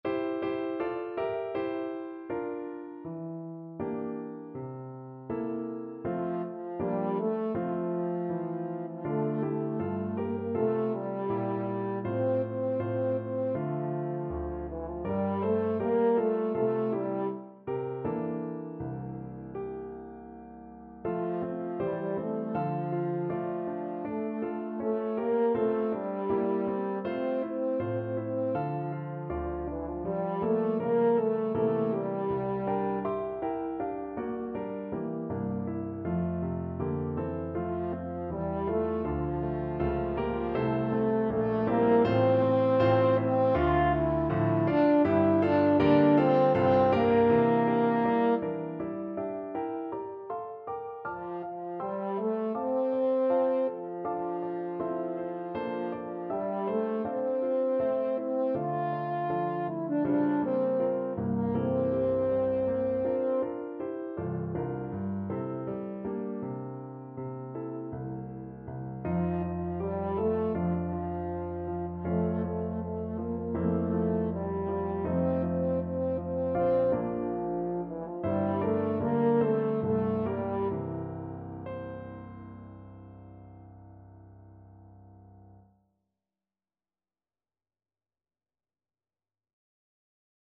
Moderato =80
2/4 (View more 2/4 Music)
E4-F5